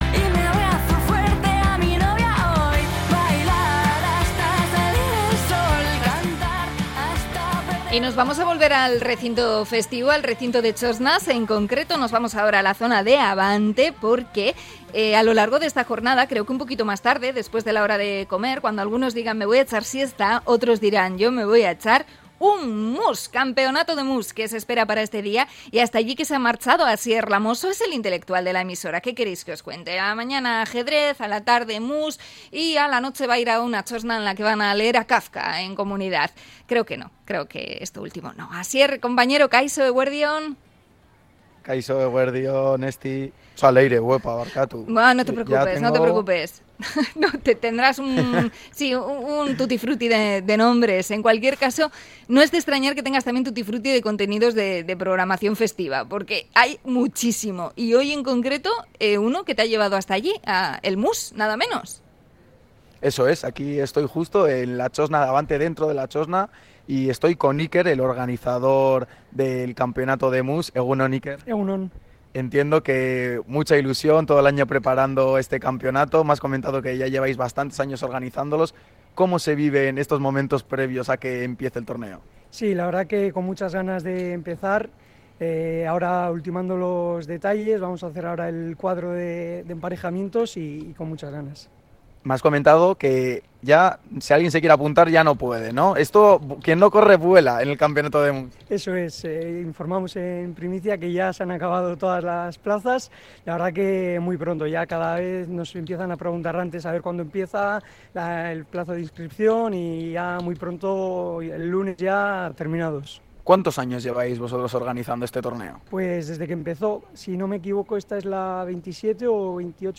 Conectamos desde la txosna de Abante